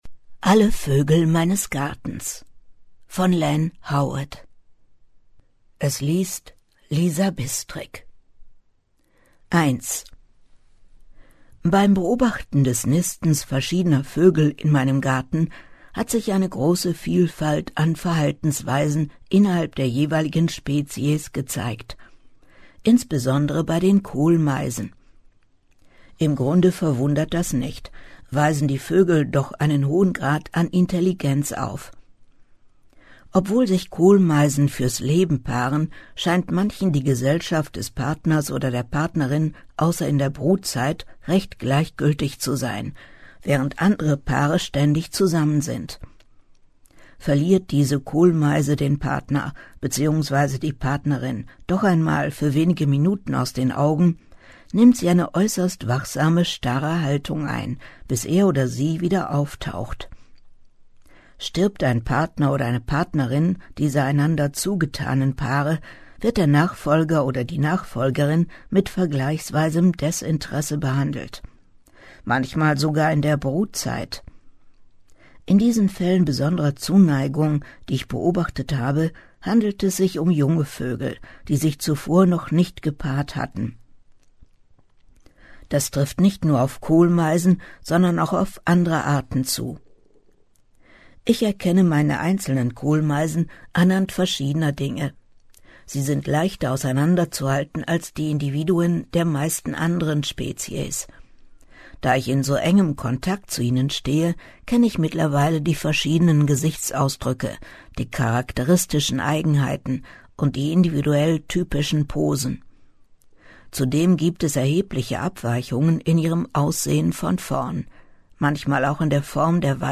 liest für Sie diese Tiergeschichten der bekannten Vogelkundlerin: